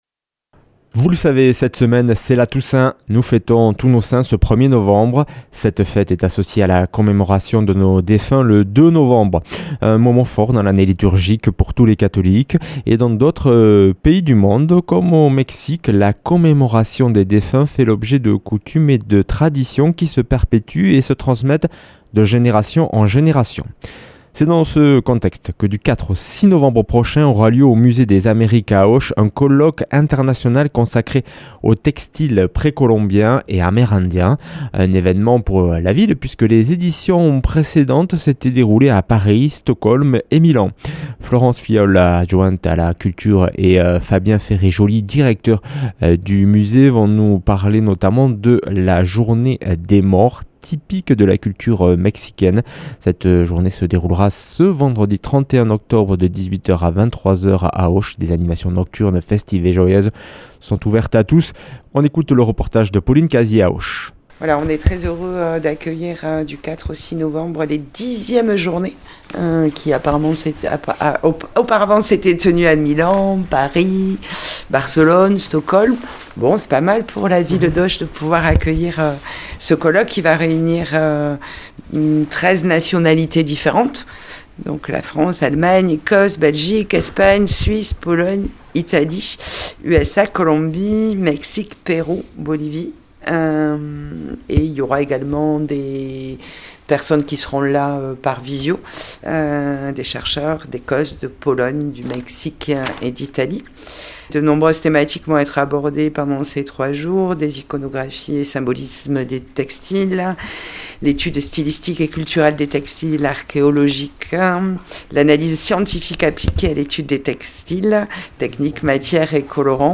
mercredi 29 octobre 2025 Interview et reportage Durée 10 min